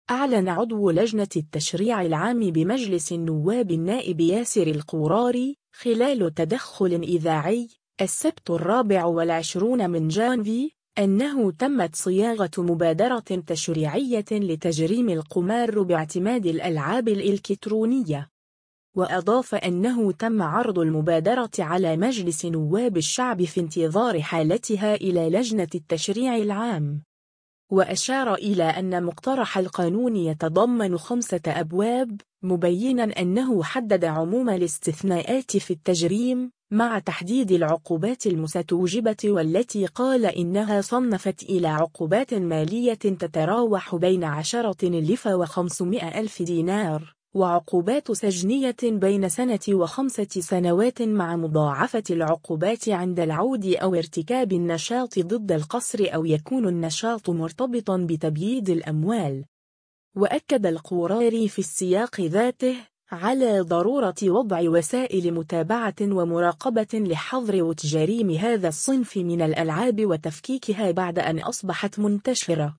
أعلن عضو لجنة التشريع العام بمجلس النواب النائب ياسر القوراري، خلال تدخل إذاعي، السبت 24 جانفي، أنه تمت صياغة مبادرة تشريعية لتجريم القمار باعتماد الألعاب الالكترونية.